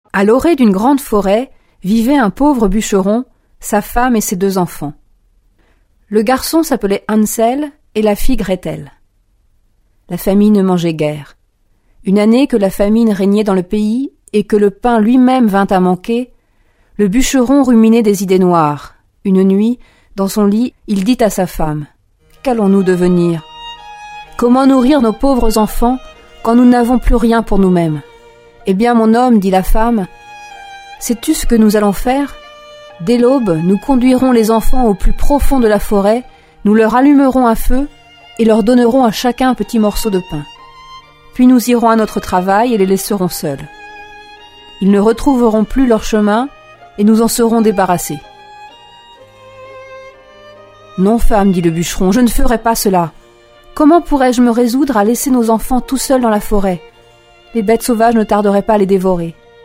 Diffusion distribution ebook et livre audio - Catalogue livres numériques
Musique : Les 4 saisons de Vivaldi